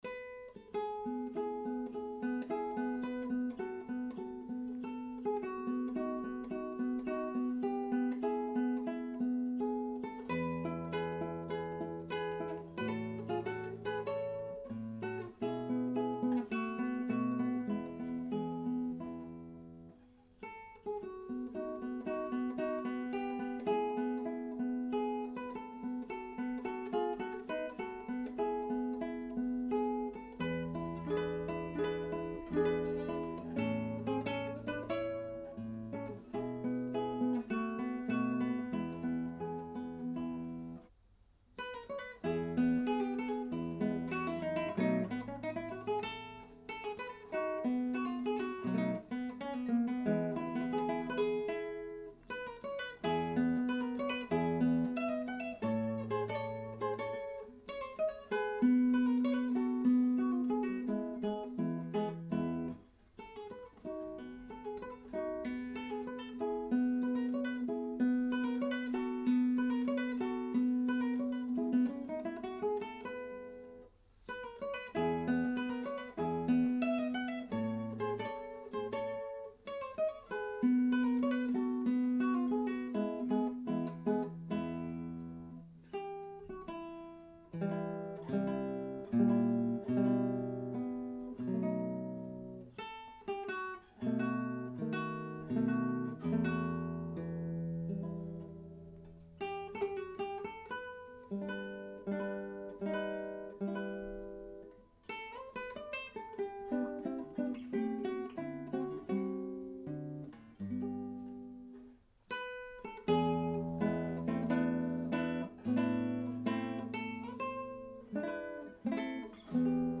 Attached is a recording my son has done in his flat in France using a fairly cheap USB microphone positioned about a mtr from the guitar. That distance is the reason for the low level. something that bothers beginners but it really does not matter so long as,..
The room is not SO badly damped that is sounds like an Olympic pool. He is fortunate to have a good sized room with a high ceiling and though sparsely furnished, there is enough absorbent, at least it sounds quite pleasant to me?